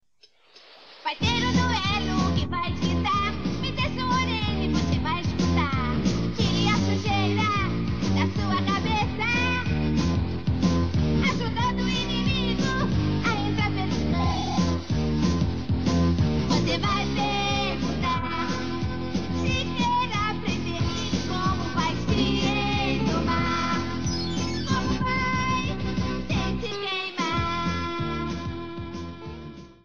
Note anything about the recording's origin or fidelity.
This is a sample from a copyrighted musical recording.